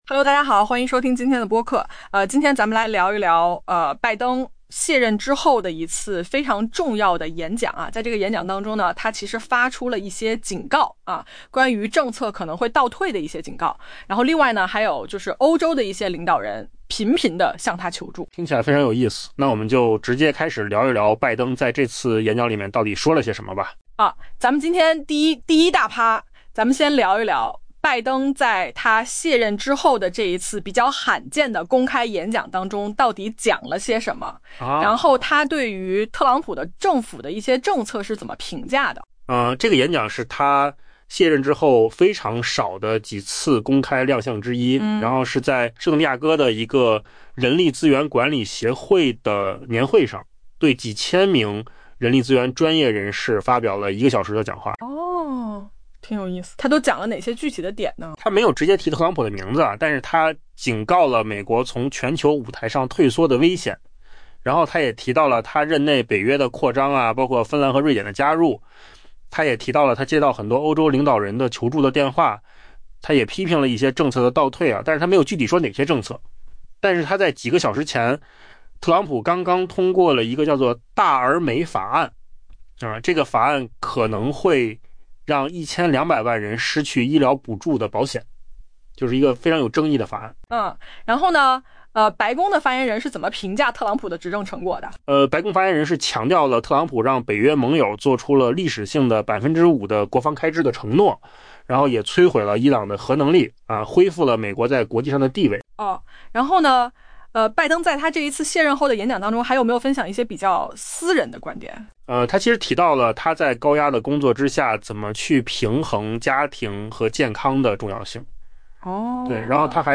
AI播客：换个方式听新闻.mp3 下载mp3
音频由扣子空间生成